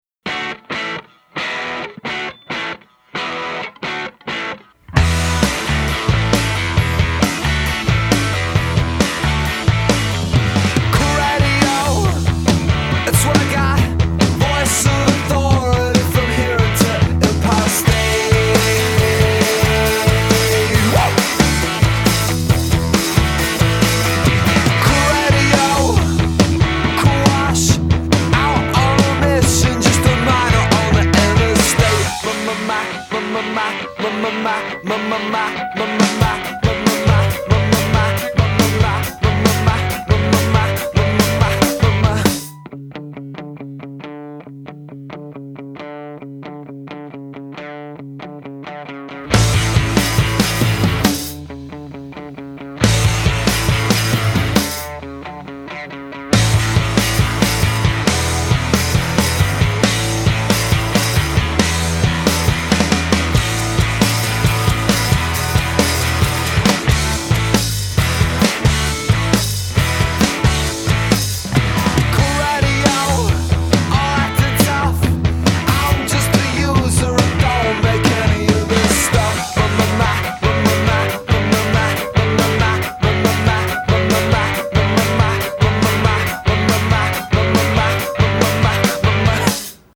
A big rocker with horns.